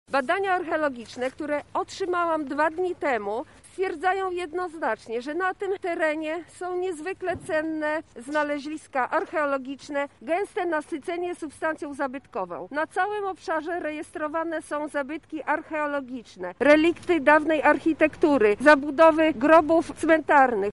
• mówi posłanka Marta Wcisło.